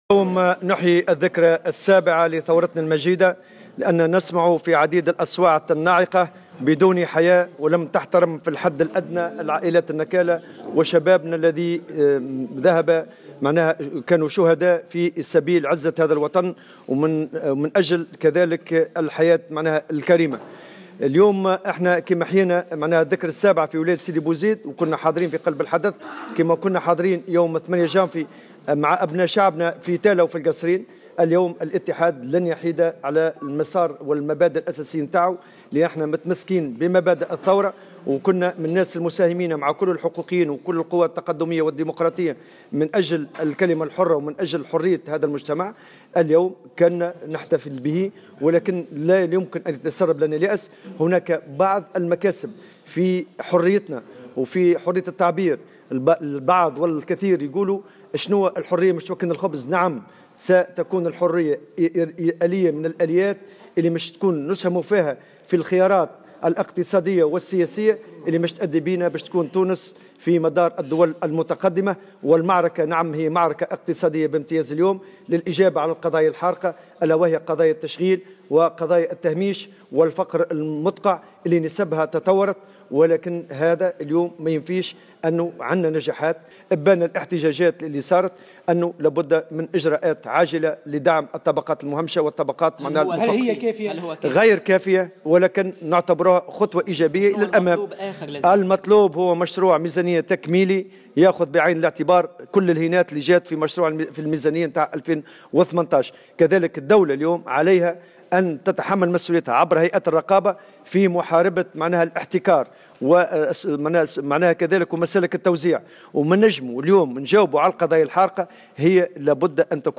على هامش فعاليات إحياء الذكرى السابعة للثورة التونسية
وتابع في سياق متصل مستخدما عبارة من اللهجة الدارجة التونسية : يجب "تفريك الرمانة" وتوجيه الدعم إلى مستحقيه.